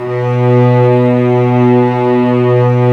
Index of /90_sSampleCDs/Roland LCDP13 String Sections/STR_Vcs II/STR_Vcs6 f Amb